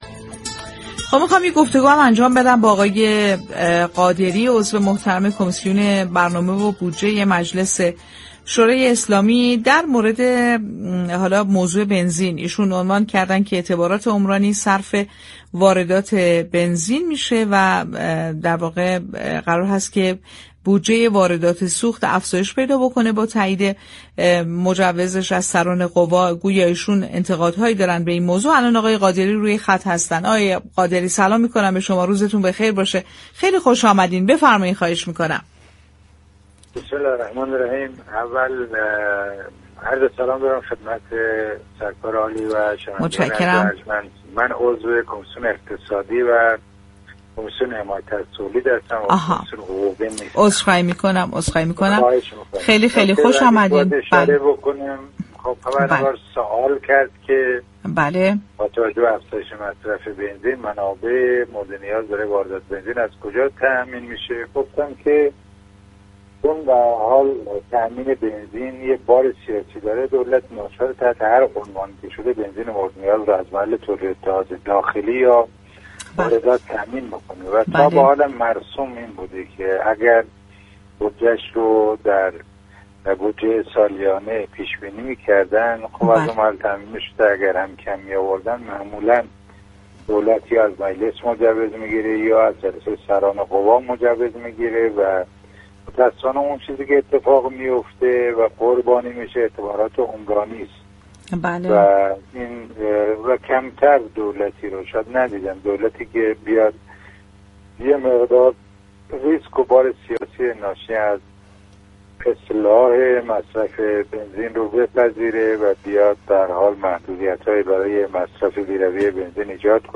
به گزارش پایگاه اطلاع رسانی رادیو تهران، جعفر قادری، عضو كمیسیون اقتصادی و حمایت از تولید مجلس شورای اسلامی در گفتگو با برنامه «بازار تهران» رادیو تهران از افزایش بودجه واردات بنزین و تأثیر آن بر اعتبارات عمرانی خبر داد و تأكید كرد دولت به دلیل فشار سیاسی ناچار است منابع داخلی را صرف واردات سوخت كند.